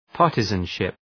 Προφορά
{‘pɑ:rtızən,ʃıp} (Ουσιαστικό) ● κομματισμός